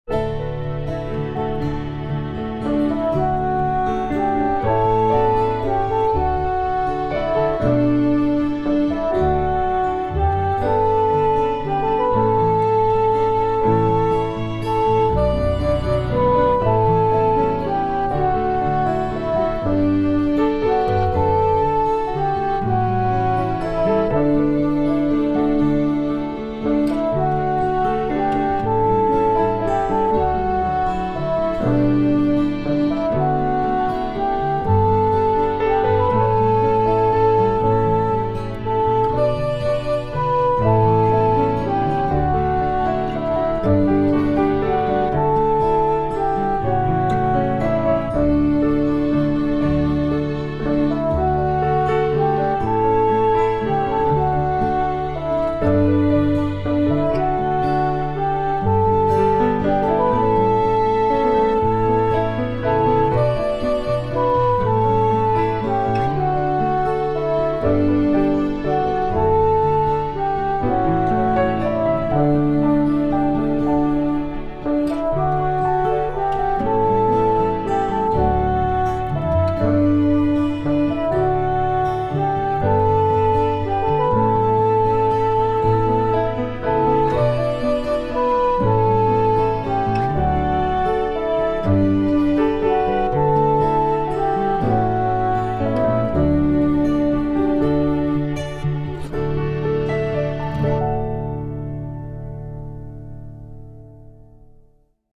With a tune with a lilt attributed to St Columba and a text from Edwin Hatch what’s not to like.